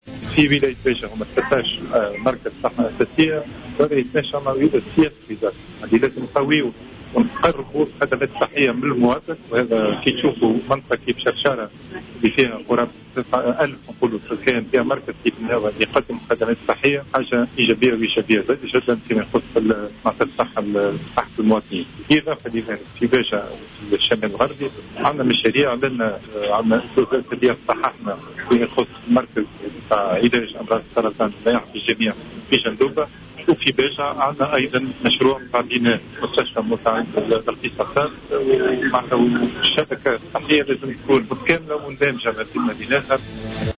وزير الصحة